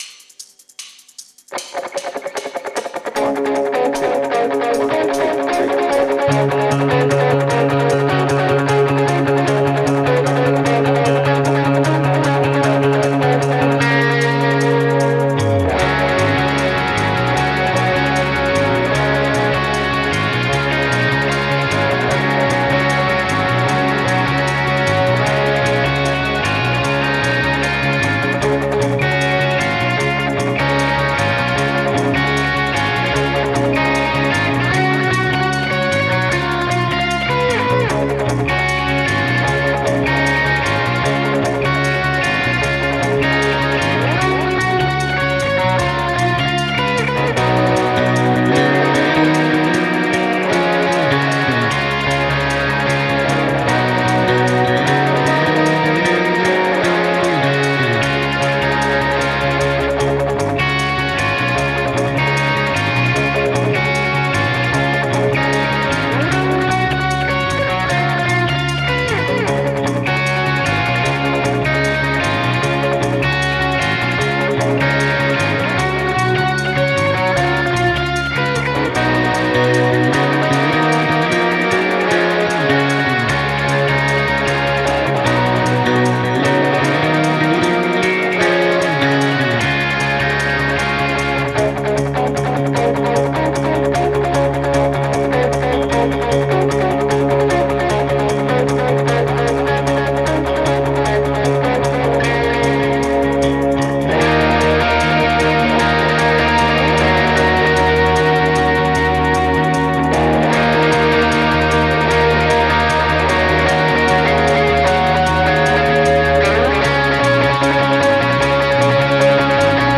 Without vocals
Based on the Vertigo Tour